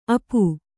♪ apu